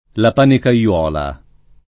[ la panikar 0 la ]